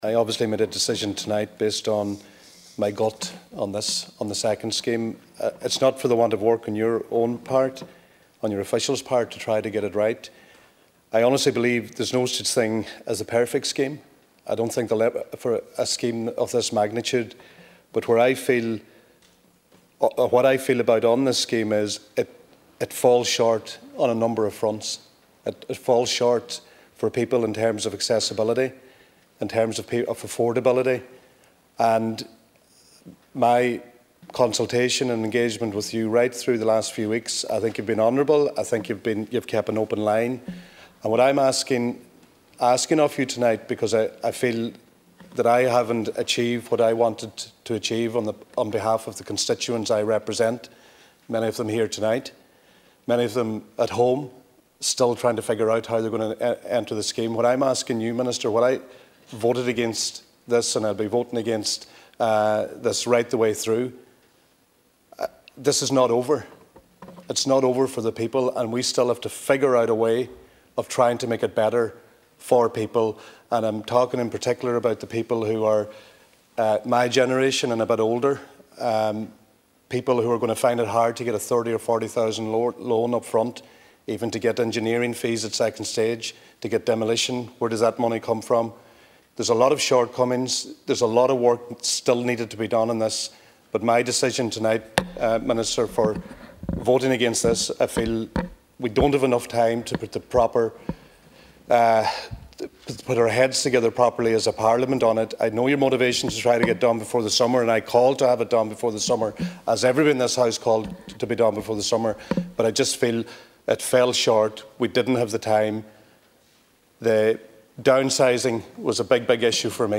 Deputy McHugh told the Dail that ultimately, he couldn’t bring himself to back the new Defective Blocks Bill………